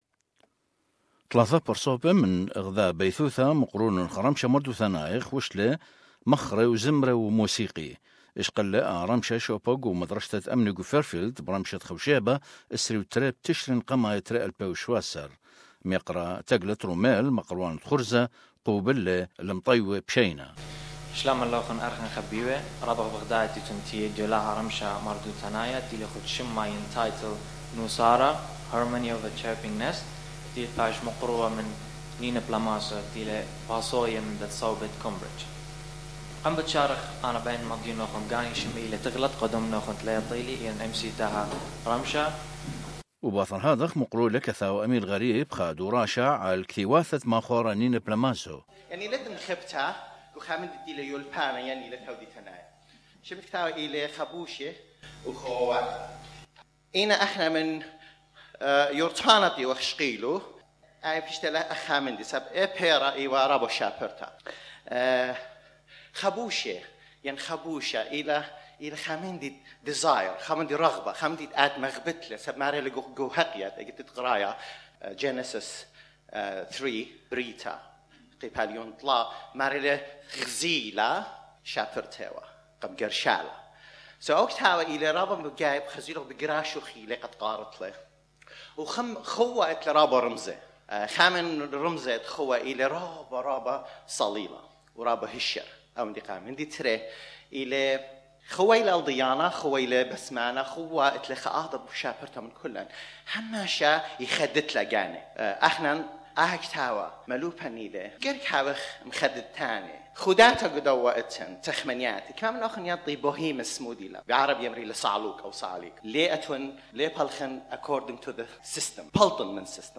lute and vocals, performed at an artistic cultural evening on the 22nd of October at Fairfield School of Art.